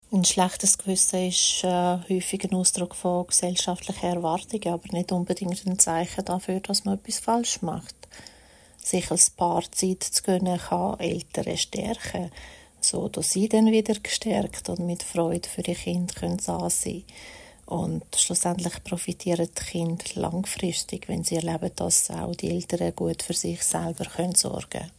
Dieses Interview gibt es auch auf Hochdeutsch!